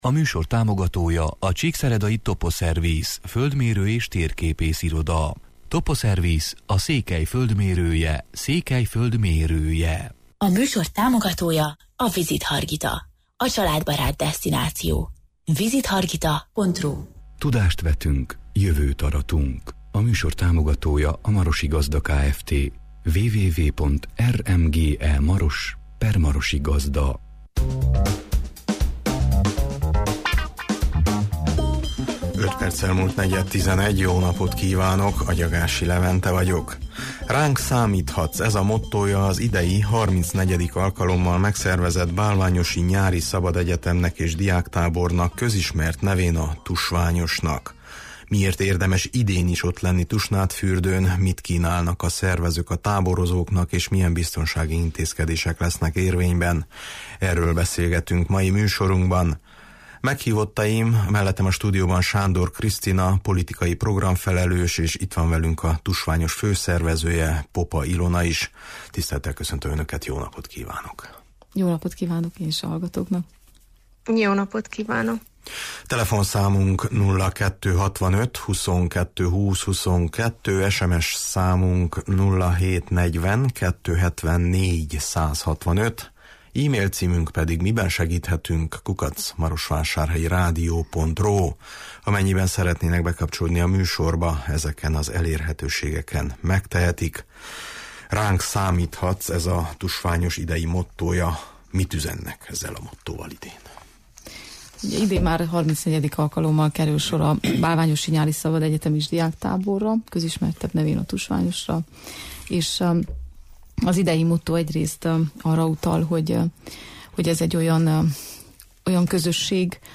Miért érdemes idén is ott lenni Tusnádfürdőn, mit kínálnak a szervezők a táborozóknak, és milyen biztonsági intézkedések lesznek érvényben? – erről beszélgetünk mai műsorunkban.